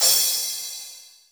CRASH CS1 -R.WAV